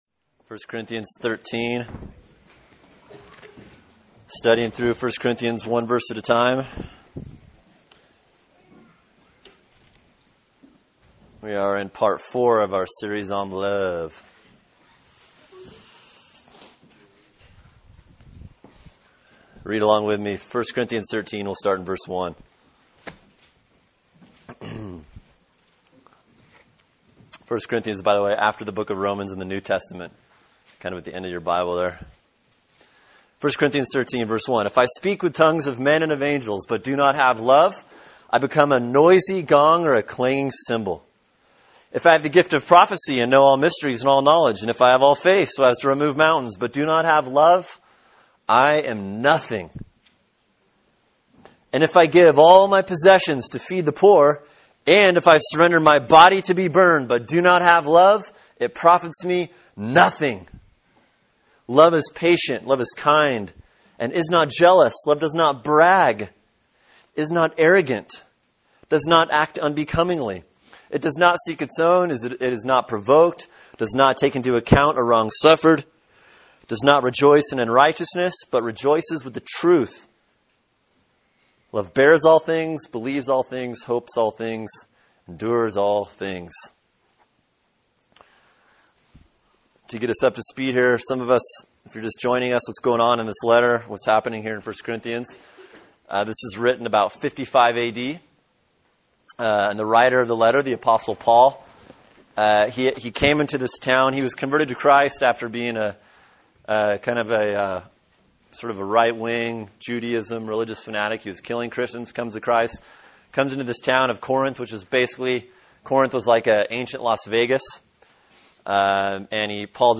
Sermon: 1 Corinthians 13:6-7 [2/20/11] | Cornerstone Church - Jackson Hole